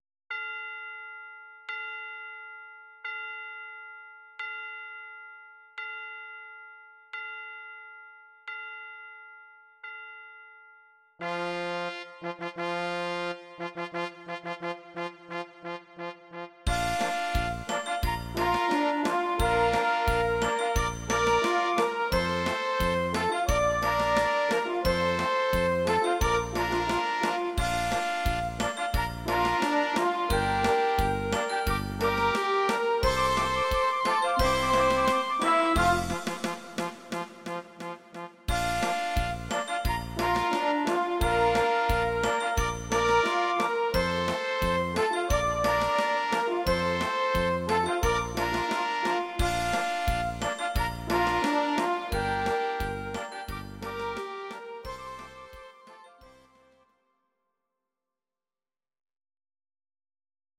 These are MP3 versions of our MIDI file catalogue.
Your-Mix: Jazz/Big Band (731)